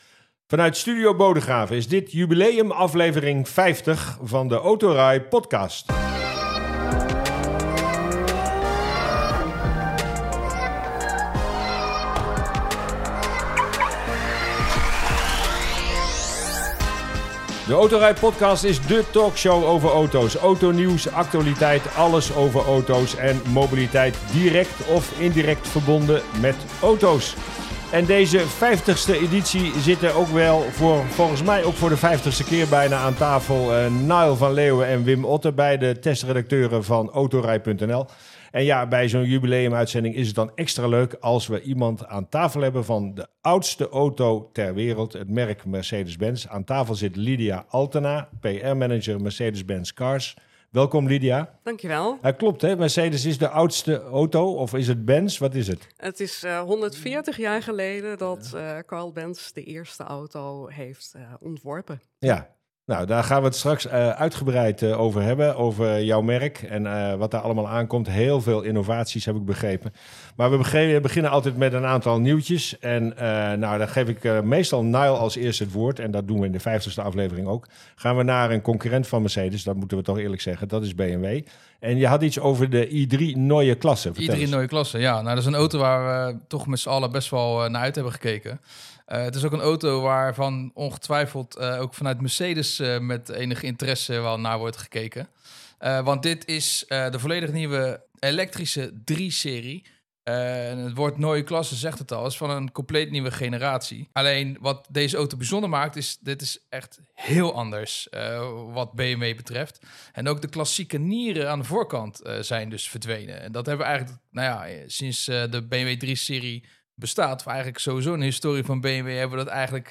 Verschillende leden van de redactie komen aan het woord, en dat levert interessante gesprekken op.